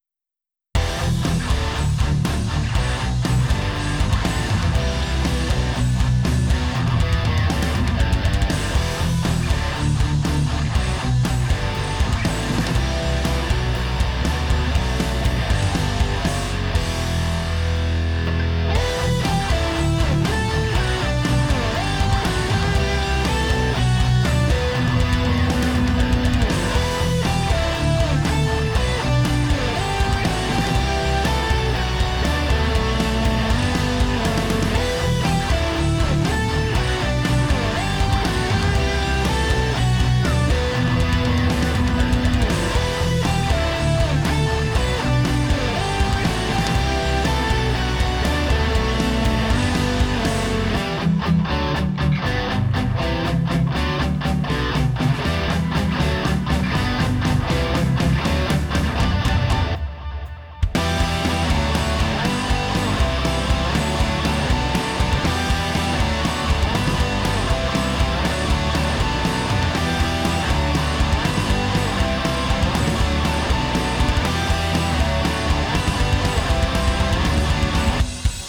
(Varning för hårdrock, eller nåt) :)
Det är dock en multibandslimiter, och det gör ju bland annat att cymbalerna inte tar så mycket stryk av peakar i basen.
Det är ett crescendo från 0.50 -> 1.00 minuter.